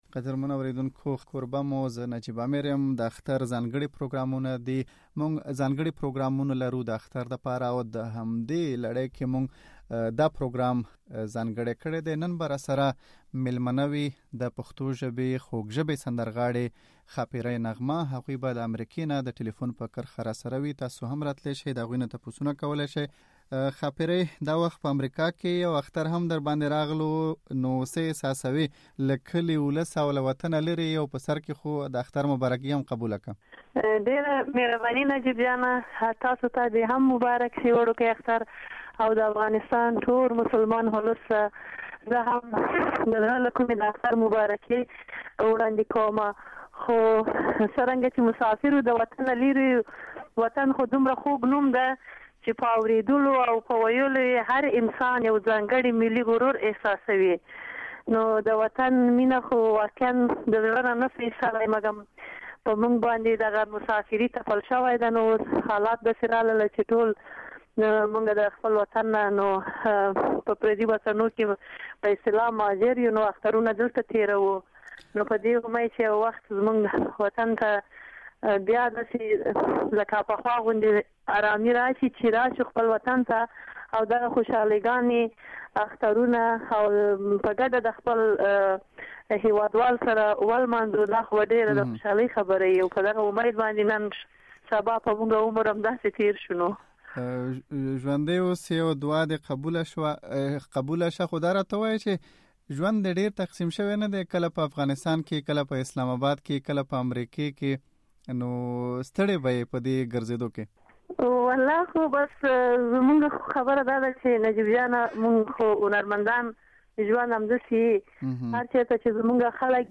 له ښاپېرۍ نغمې سره مرکه